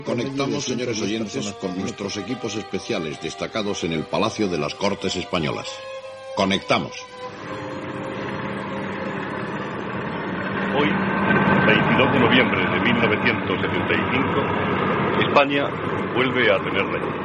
Connexió amb el Palacio de las Cortes de Madrid on es farà la proclamación de Juan Carlos I com rei d'Espanya